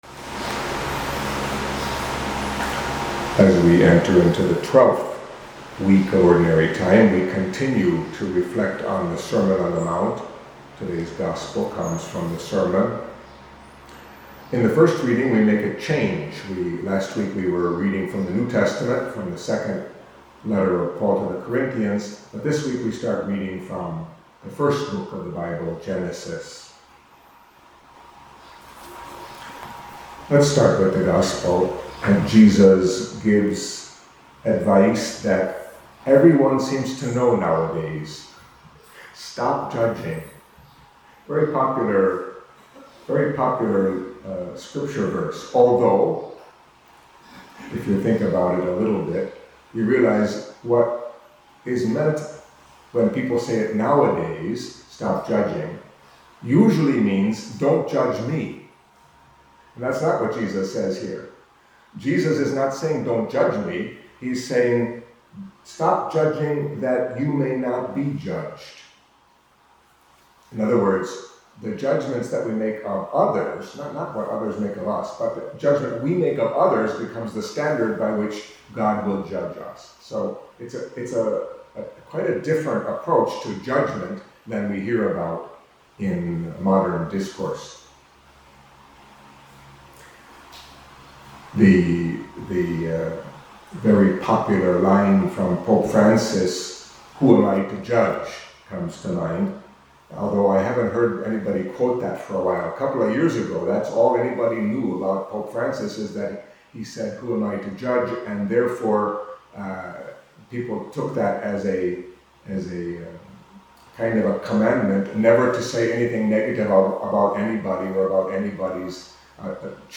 Catholic Mass homily for Monday of the 12th Week in Ordinary Time